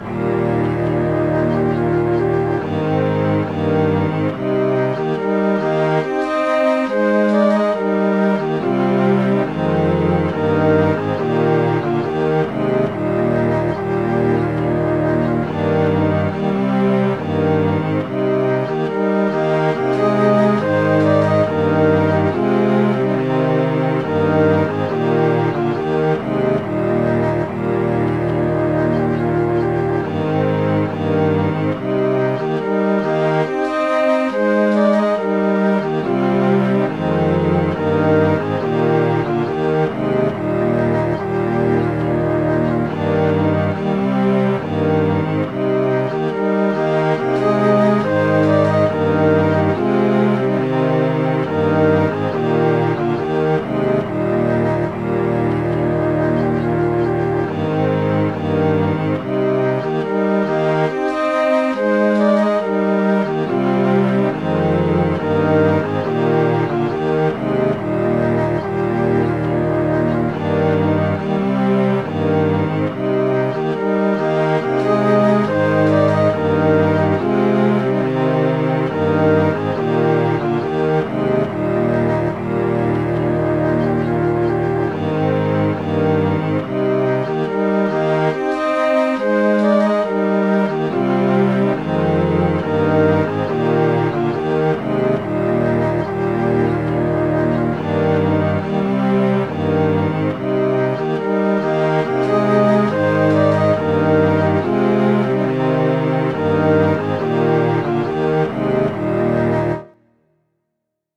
These words were written in the time after Culloden, when many loyal to the Stuart cause fled abroad. The words were matched to an old Highland air.
lenchan.mid.ogg